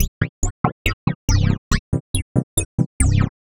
tx_synth_140_wibble_C.wav